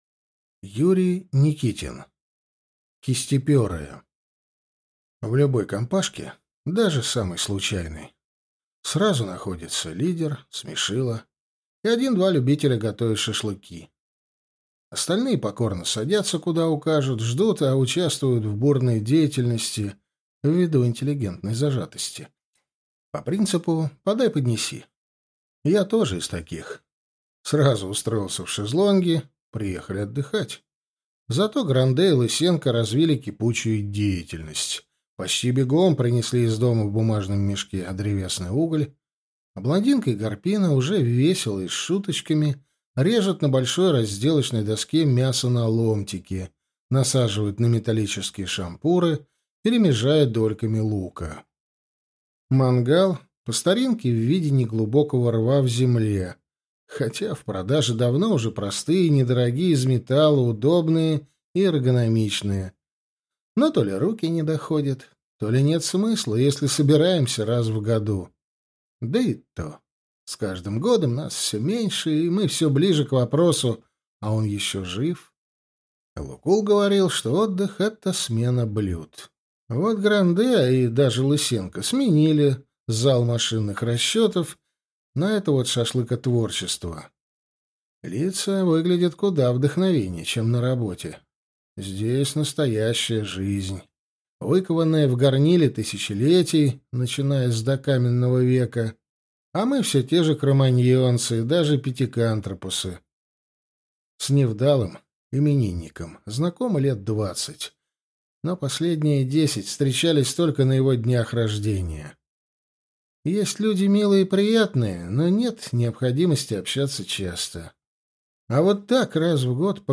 Аудиокнига Кистепёрые | Библиотека аудиокниг